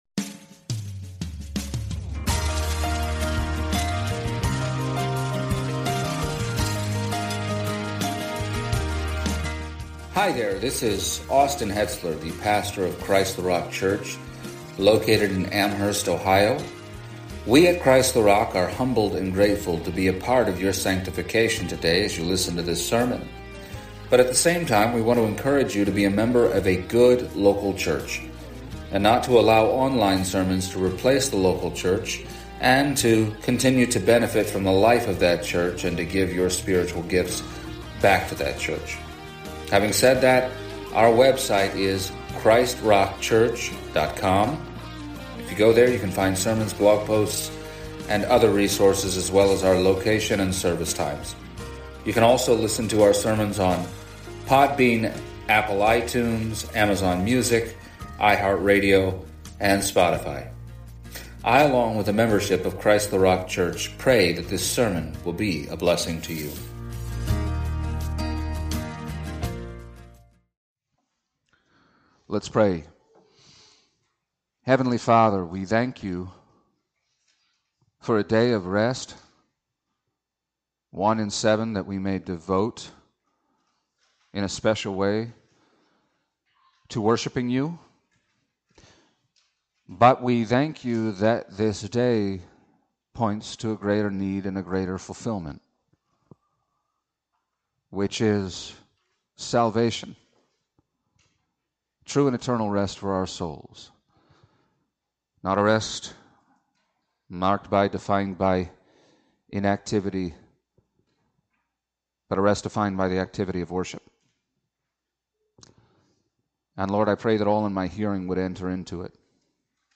Passage: Hebrews 3:12-4:13 Service Type: Sunday Morning